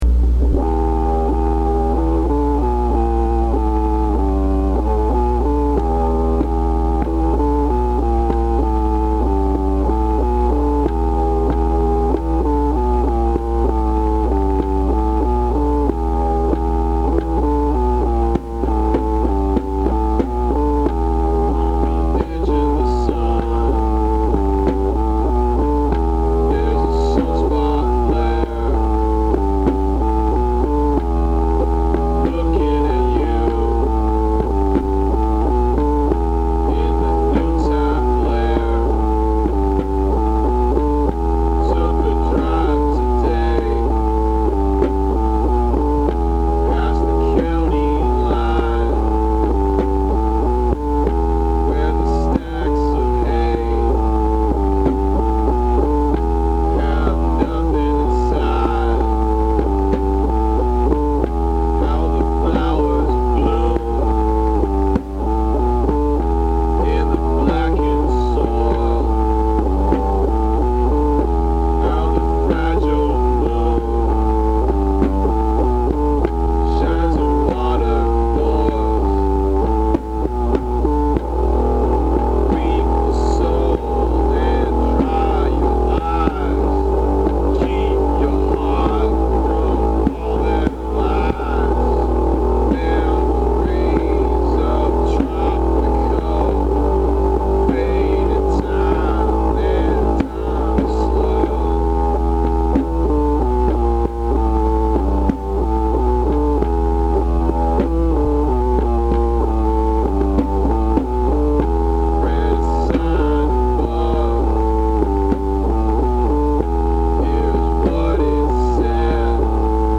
It’s a shame the sound is so crappy.